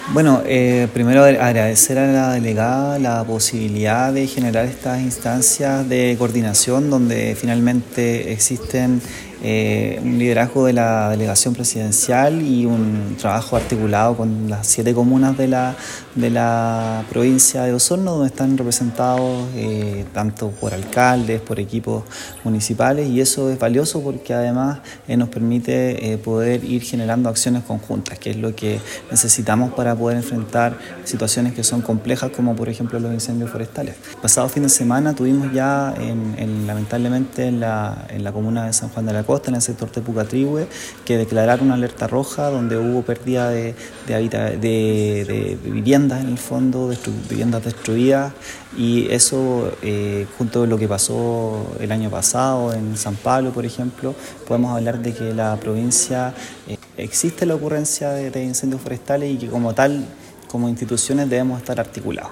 Por su parte, Cristian González, director regional (s) del Servicio Nacional de Prevención y Respuesta ante Desastres, SENAPRED, enfatizó que estas instancias de coordinación son fundamentales para articular el trabajo entre el nivel regional, los municipios y otros actores clave.